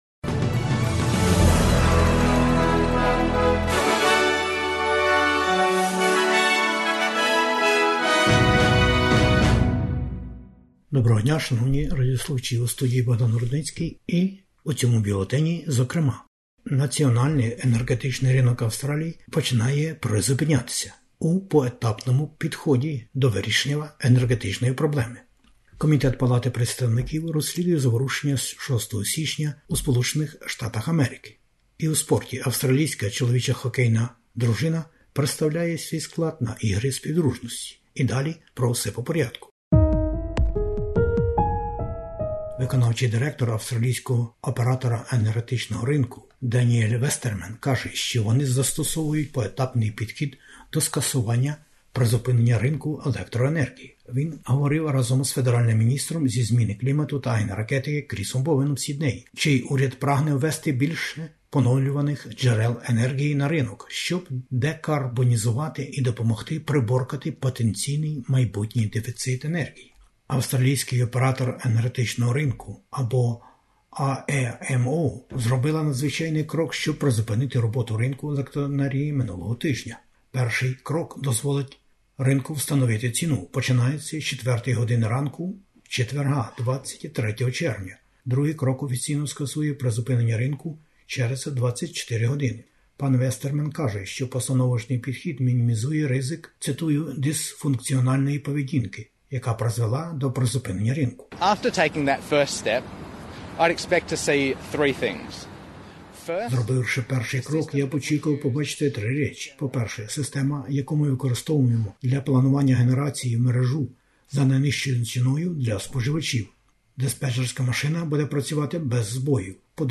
Бюлетень SBS новин українською мовою. Кризи в енерґетиці не повинно бути - Федеральний уряд Австралії про енерґоринок.